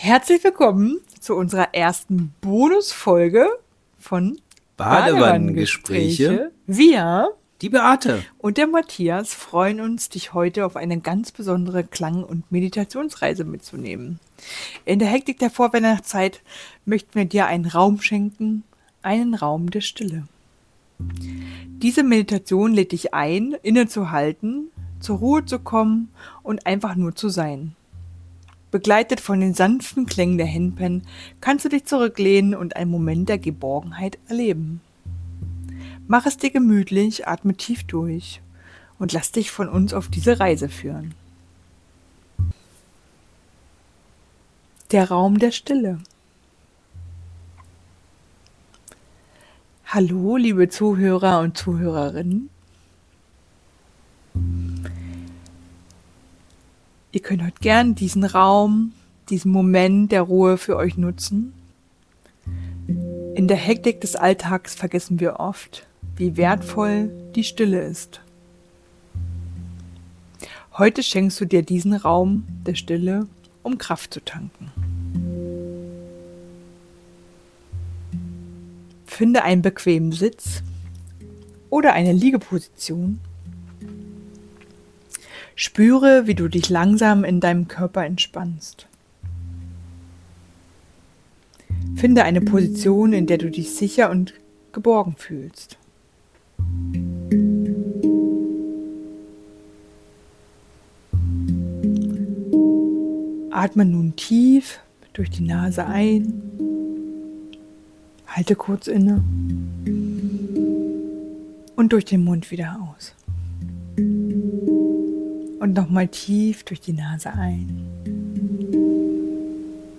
Der Raum der Stille: Eine Handpan-Klangreise